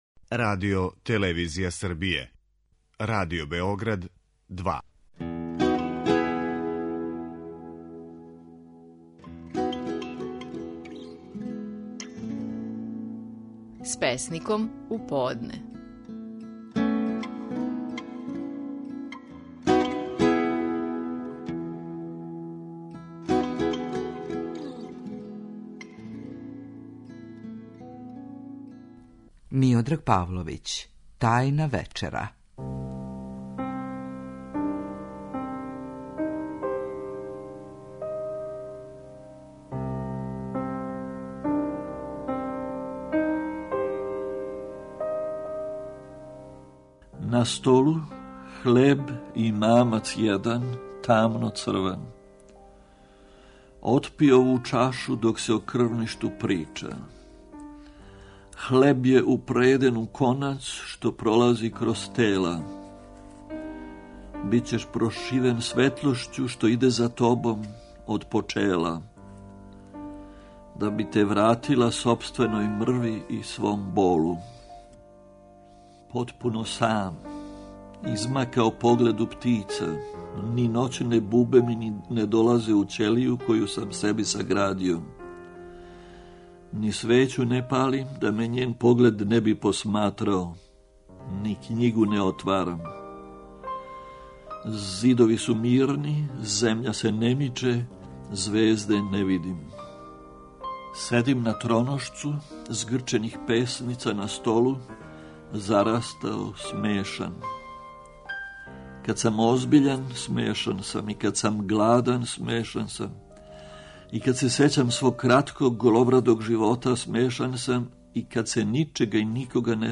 Стихови наших најпознатијих песника, у интерпретацији аутора.
Миодраг Павловић говори своју песму „Тајна вечера".